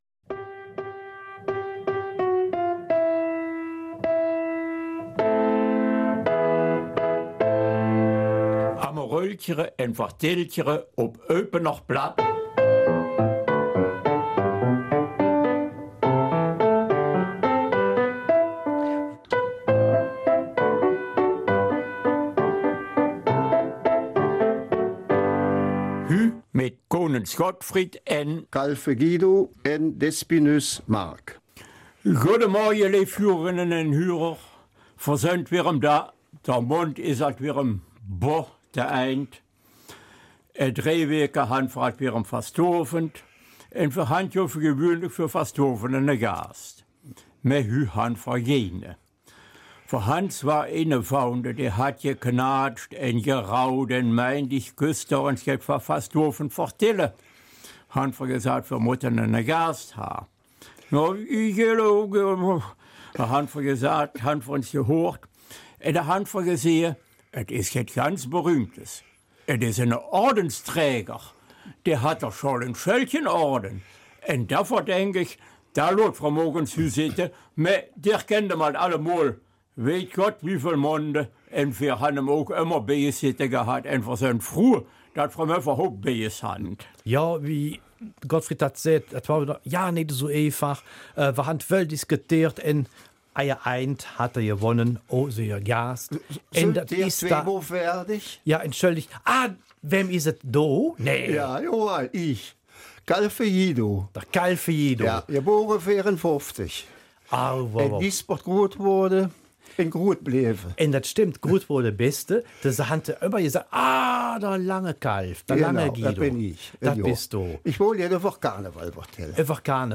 Eupener Mundart: Eine jecke Zeit
Werte Zuhörerinnen und Zuhörer, findet mal zu Karneval einen Studiogast.
Eines vorab: Es war ein schwieriges Interview, vieles musste neu eingesprochen werden, unser Tontechniker erlitt einen Schwächeanfall.